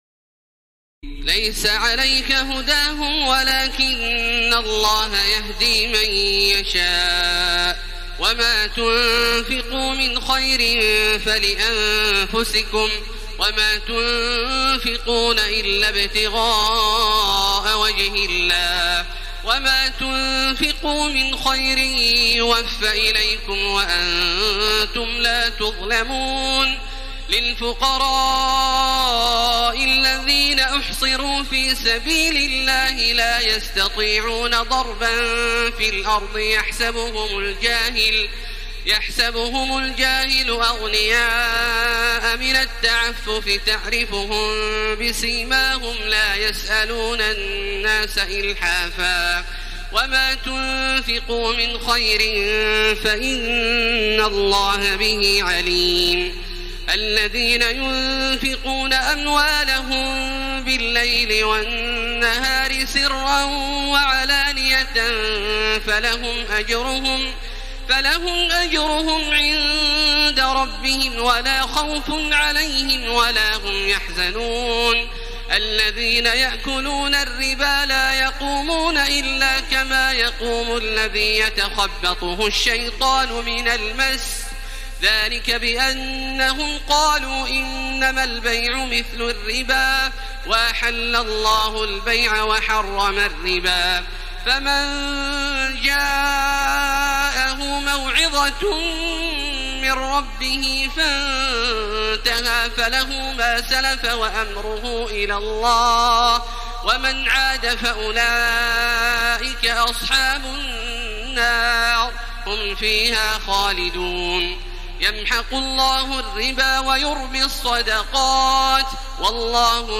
تراويح الليلة الثالثة رمضان 1434هـ من سورتي البقرة (272-286) و آل عمران (1-63) Taraweeh 3st night Ramadan 1434H from Surah Al-Baqara and Surah Aal-i-Imraan > تراويح الحرم المكي عام 1434 🕋 > التراويح - تلاوات الحرمين